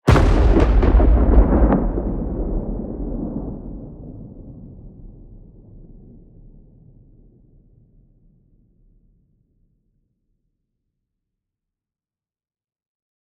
player-dead.ogg